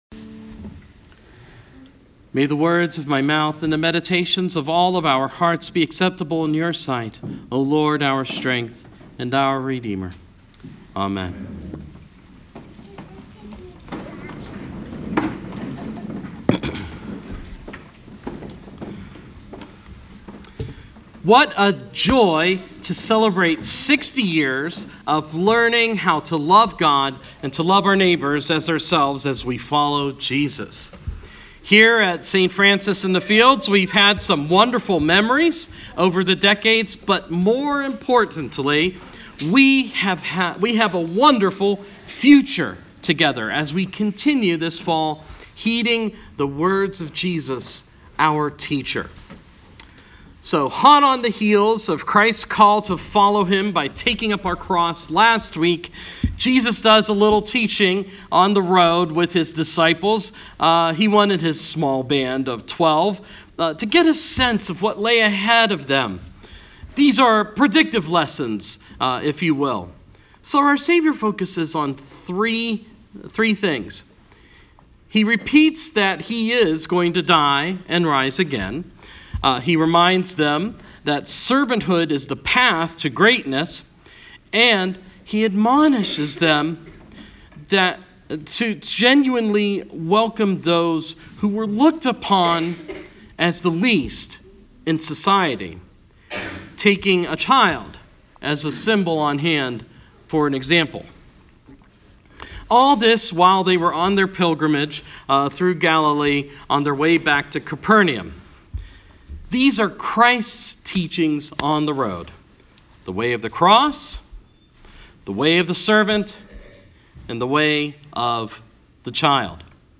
Same basic sermon at St Bartholomew’s and St Francis’ 60th Anniversary. Jesus teaches us we need to follow the way of the cross, the way of the servant, and the way of the child.